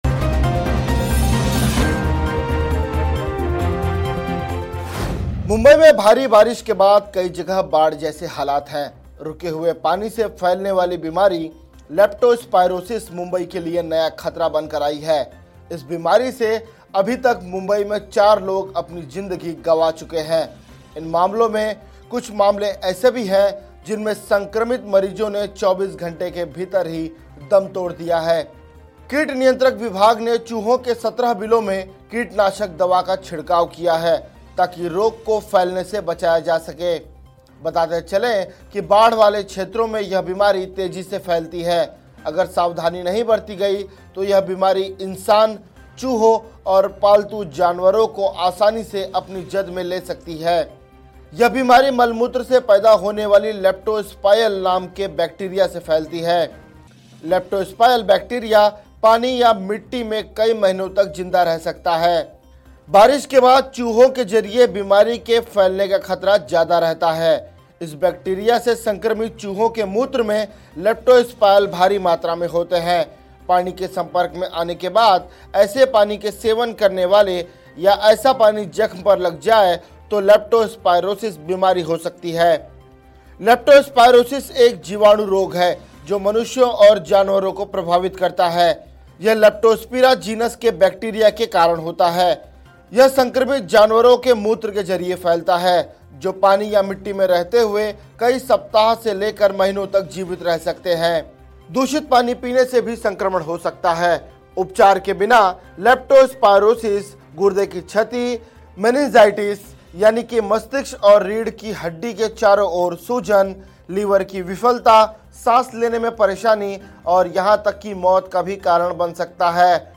न्यूज़ रिपोर्ट - News Report Hindi / बारिश में मौत का वायरस, Nipah Virus से अधिक खतरनाक बीमारी से तुरंत दम तोड़ रहे मरीज